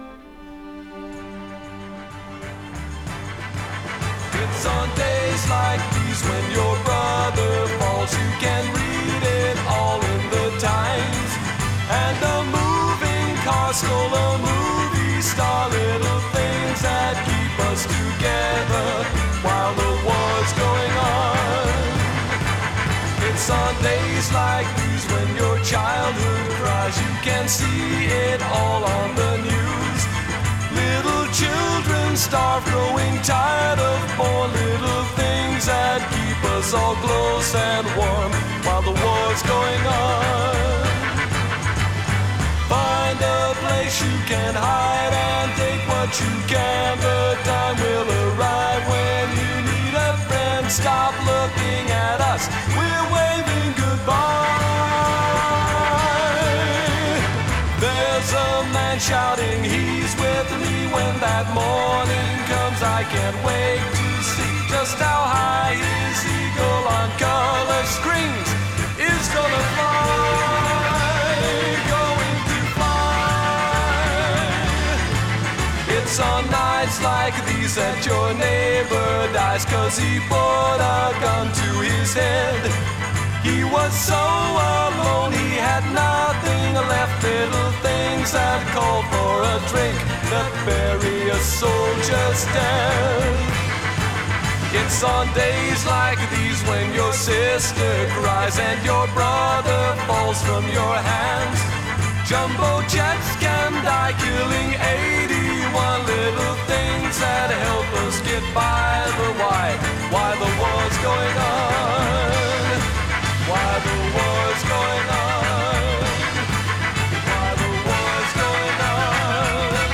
though with less gallop and more gasp